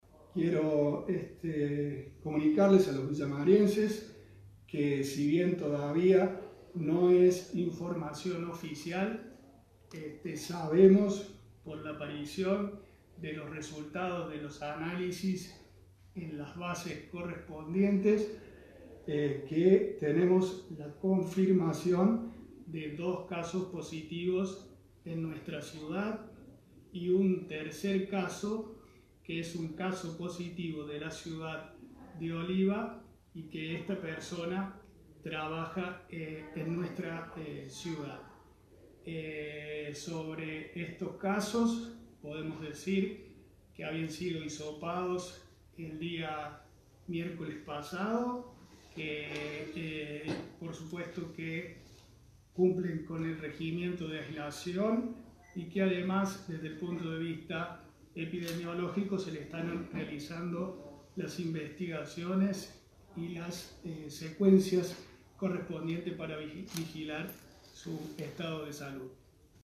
El intendente Pablo Rosso adelantaba anoche los nuevos casos y la confirmación de la circulación comunitaria del virus.